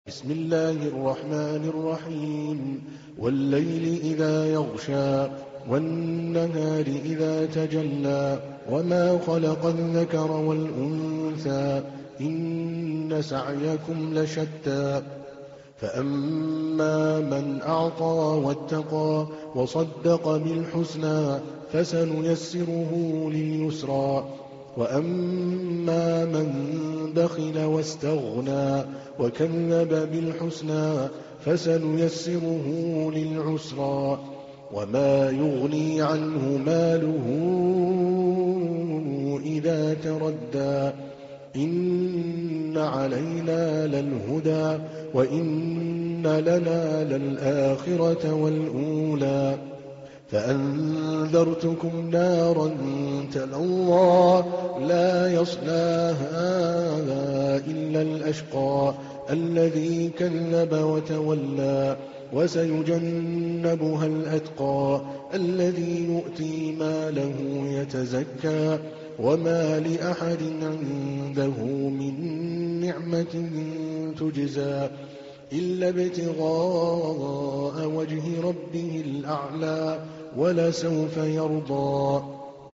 تحميل : 92. سورة الليل / القارئ عادل الكلباني / القرآن الكريم / موقع يا حسين